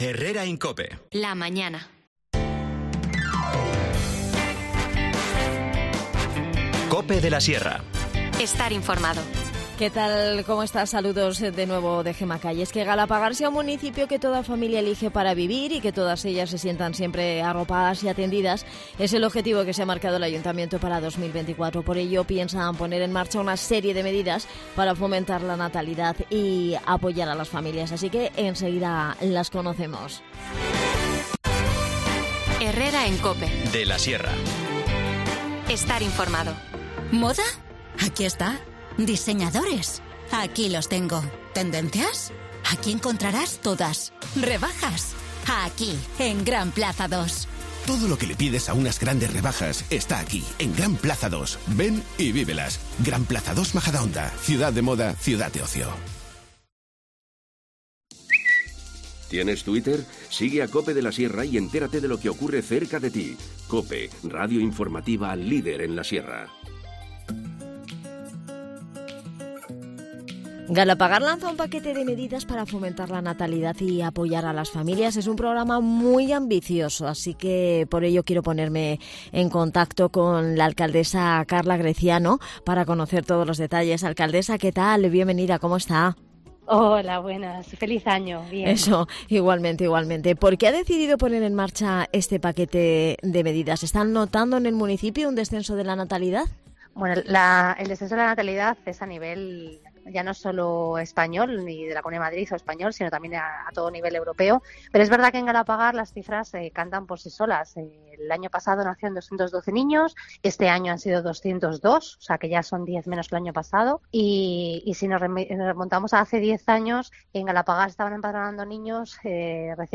Nos adelanta todas las propuestas Carla Greciano, alcaldesa de Galapagar.
Las desconexiones locales son espacios de 10 minutos de duración que se emiten en COPE, de lunes a viernes.